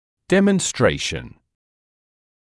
[ˌdemən’streɪʃn][ˌдэмэн’стрэйшн]демонстрация, показ; выявление